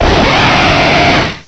tyrantrum.aif